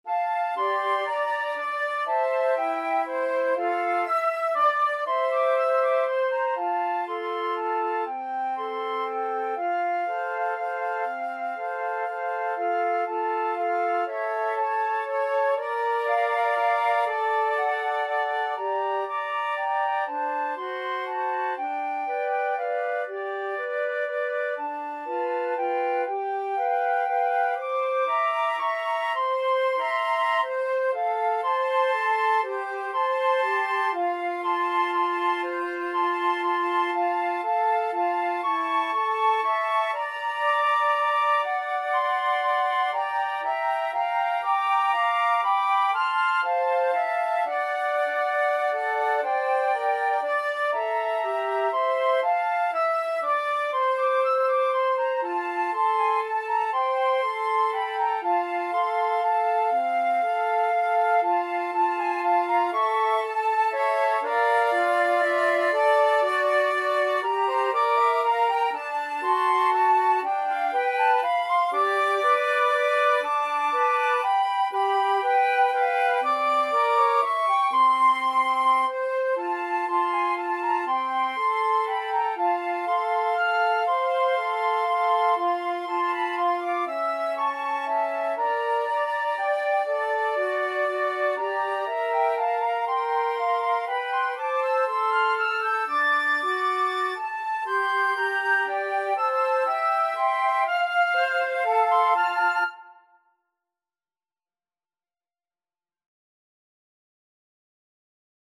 Flute 1Flute 2Flute 3
= 120 Tempo di Valse = c. 120
3/4 (View more 3/4 Music)
Jazz (View more Jazz Flute Trio Music)